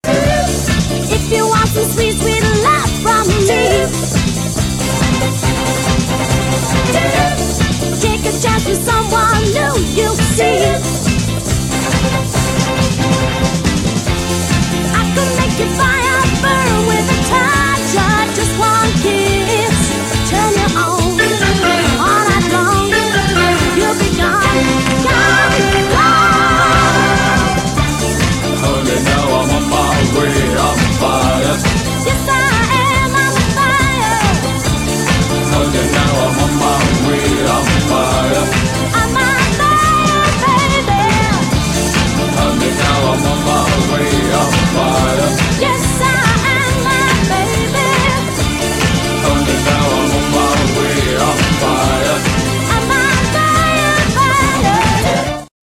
La version originale